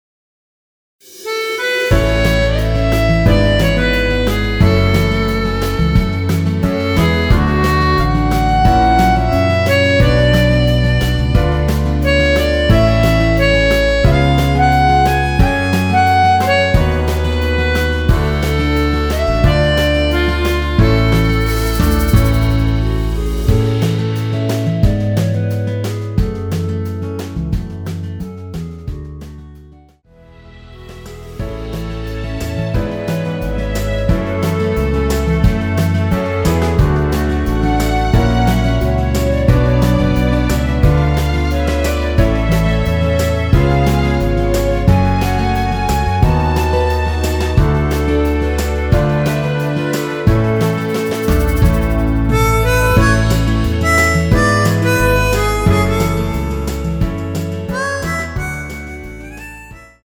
원키에서(-1)내린 멜로디 포함된 MR입니다.(미리듣기 확인)
앞부분30초, 뒷부분30초씩 편집해서 올려 드리고 있습니다.
중간에 음이 끈어지고 다시 나오는 이유는